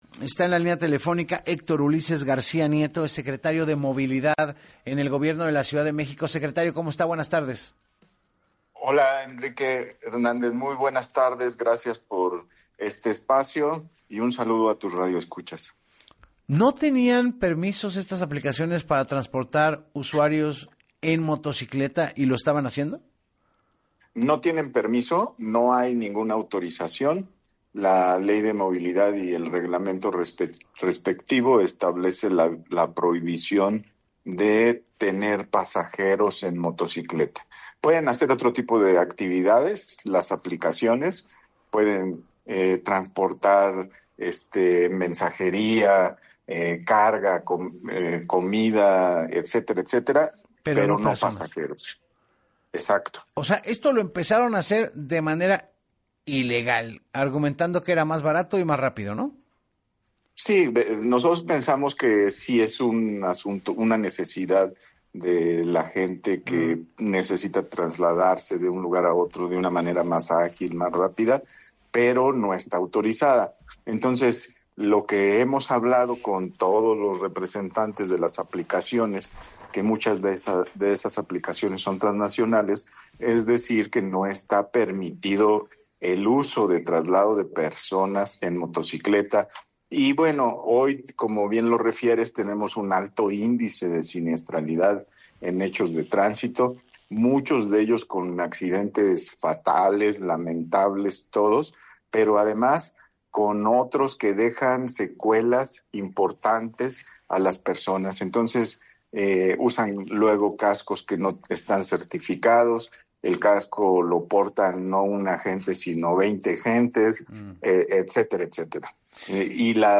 En entrevista con Enrique Hernández Alcázar, el secretario de Movilidad de la Ciudad de México, Héctor Ulises García Nieto, dijo que las aplicaciones no tienen ningún permiso, ni autorización en la ley de movilidad y en el reglamento para transportar pasajeros en moto, está prohibido, solo está autorizado actividades de mensajería, y entrega de comida.